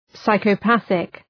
Προφορά
{,saıkə’pæɵık}